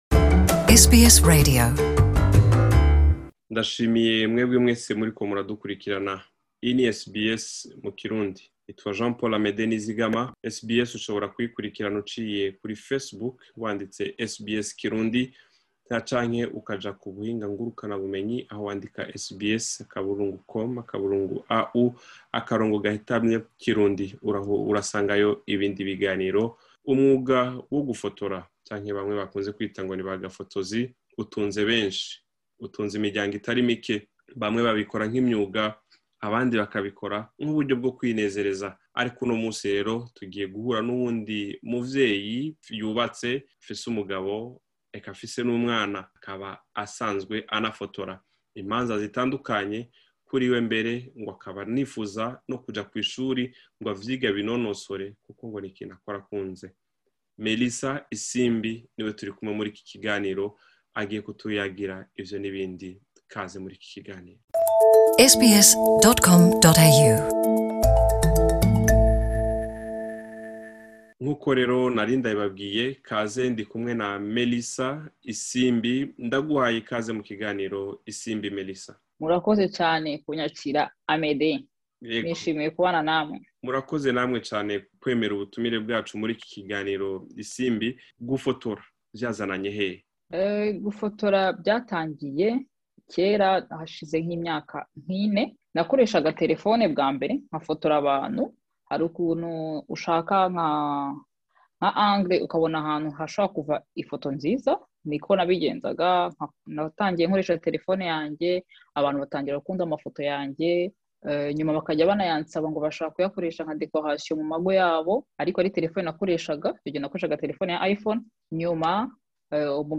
Abantu benshi basigaye baratahuye gukora ibintu ubibangikanije yaba mu kazi canke mu rugo. gufata amasanamu n'igikorwa gisaba kwitonda, kuko vyoroshe guhusha ntusubirize ayo masanamu. Umwe mu bakenyezi bakora umwuga wo gufata amasanamu yavuganye na SBS kirundi.